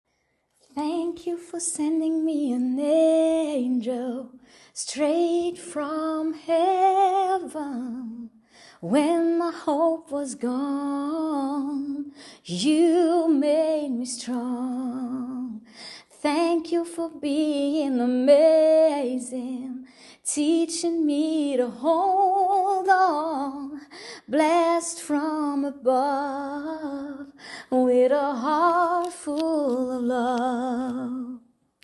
Gesänge